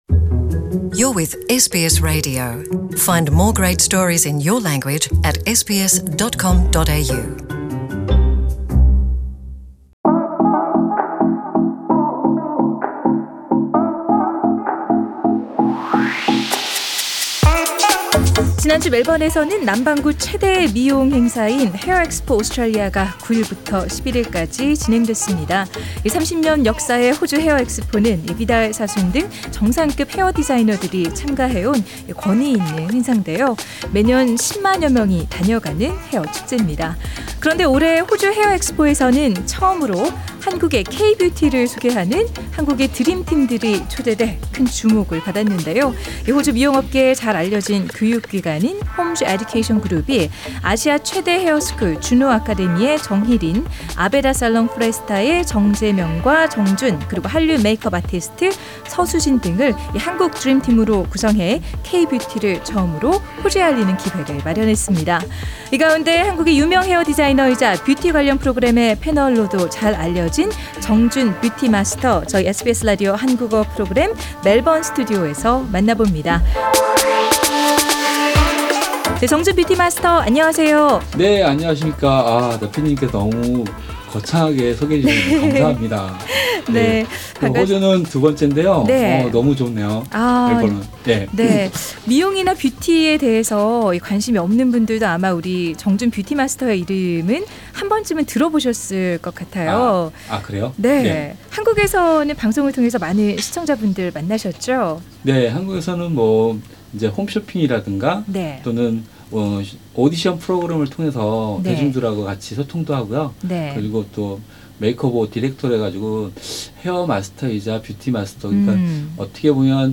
SBS Korean Program